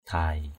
/d̪ʱaɪ:/ (t.) dài = long. aw dhai a| =D áo dài.